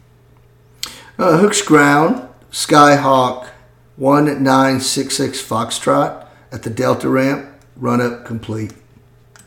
Aviation Radio Calls